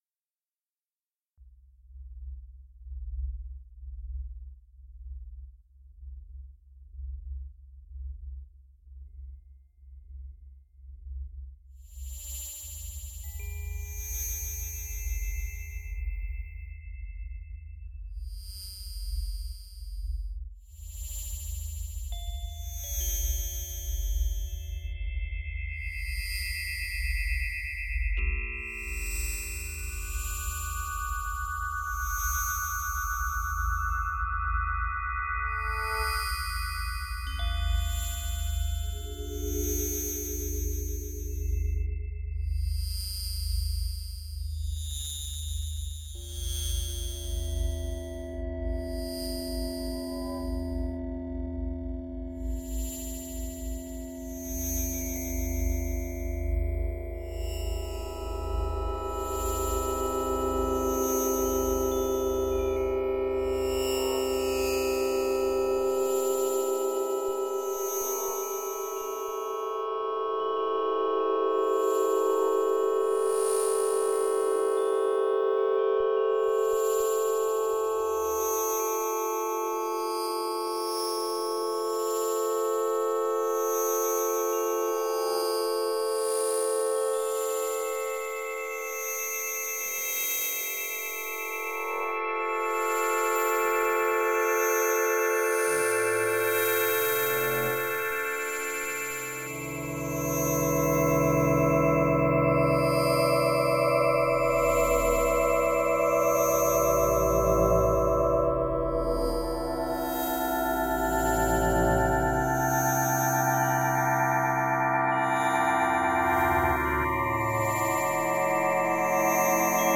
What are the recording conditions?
Stereo Mix